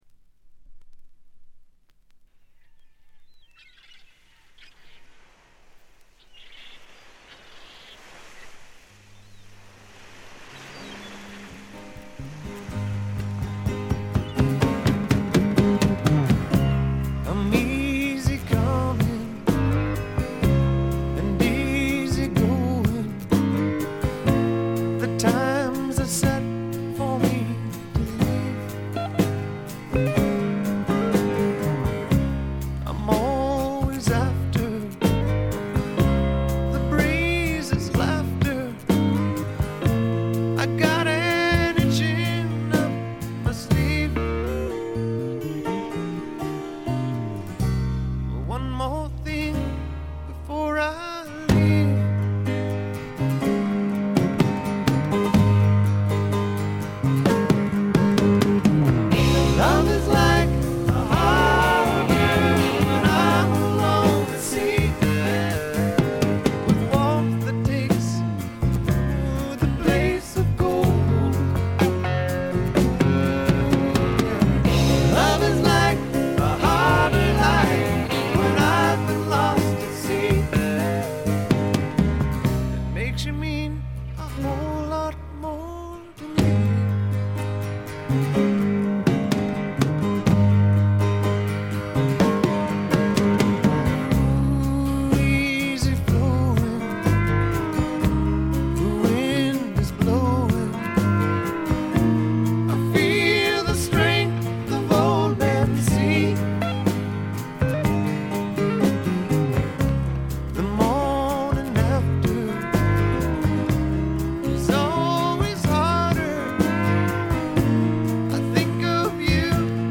部分試聴ですがほとんどノイズ感無し。
試聴曲は現品からの取り込み音源です。
Banjo, Harp
Fiddle
Steel Guitar